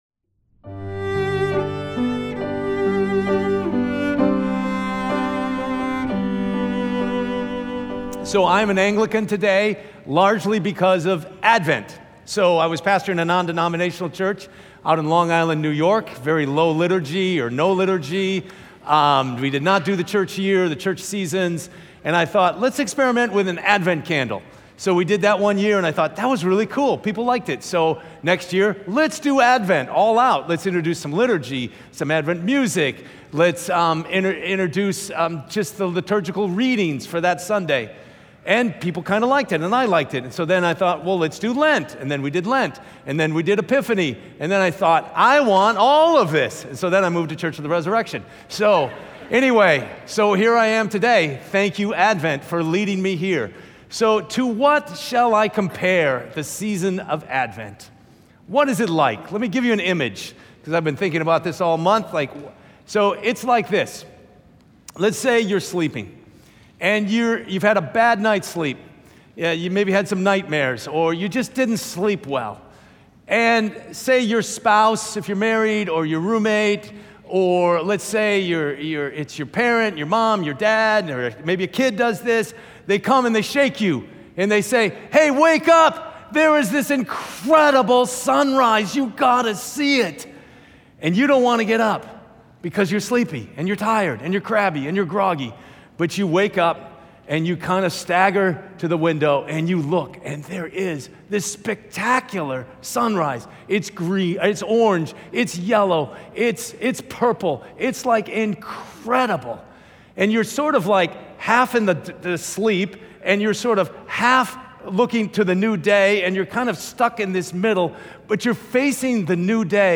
Sermon Messages from Church of the Resurrection in Wheaton, IL